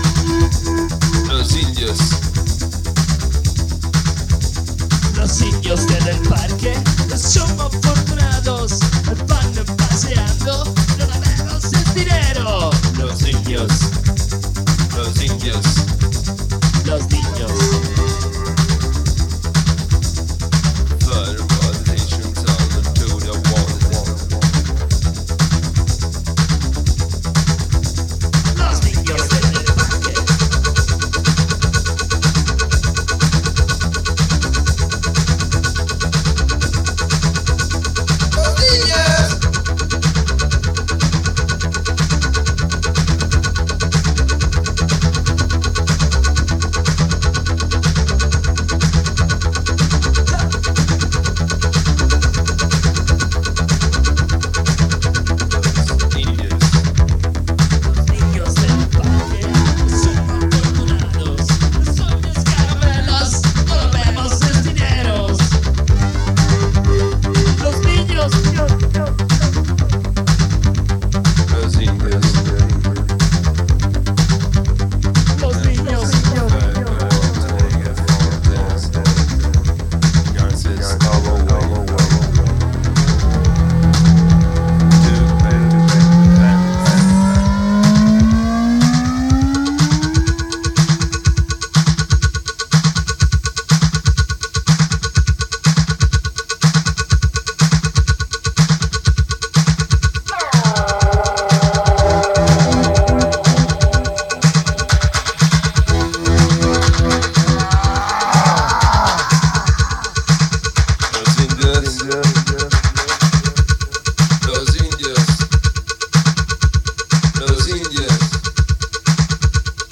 ダーティーなダブ処理を施されて飛び交うヴォーカル、シンセが原曲よりも荒々しく不吉な仕上がりがヤバい。